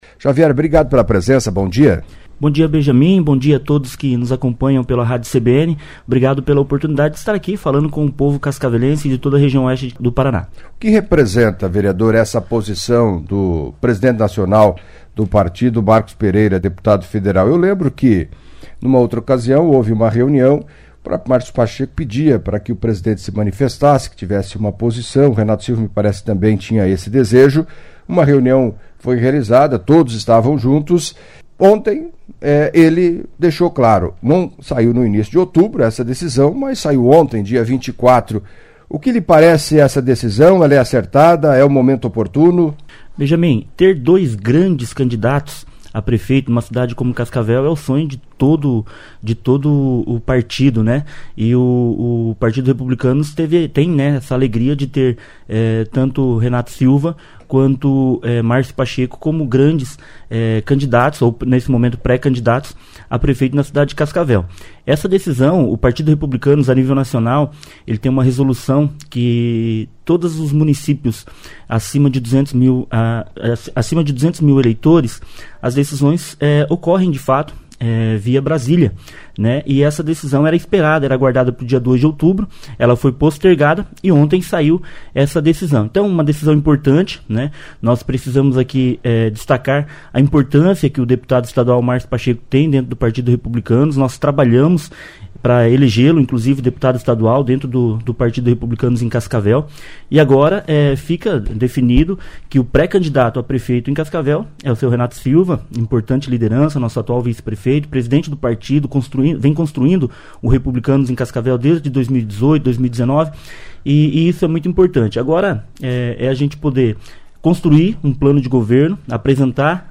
Em entrevista à CBN Cascavel nesta quarta-feira (25) o vereador Carlos Xavier, do Republicanos, destacou a importância do anúncio feito na tarde de terça-feira (24), em Brasília, pelo presidente nacional do Republicanos, Marcos Pereira, deputado federal de São Paulo.